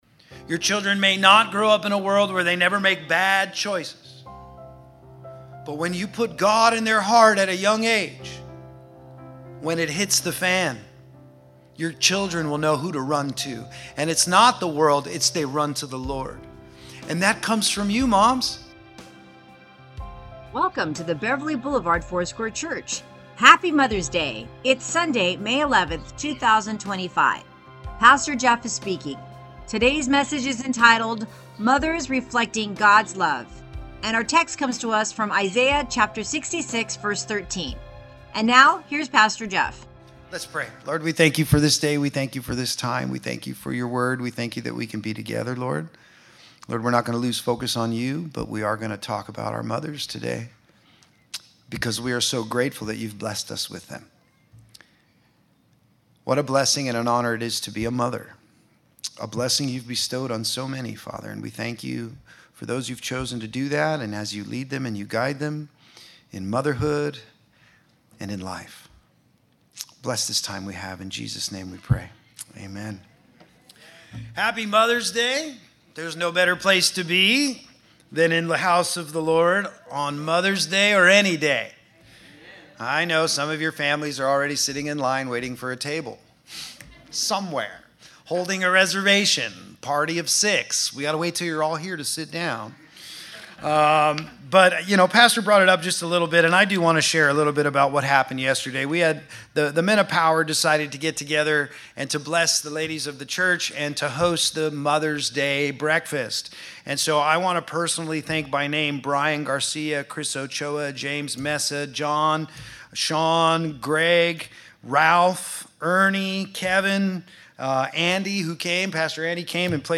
Sermons | Beverly Boulevard Foursquare Church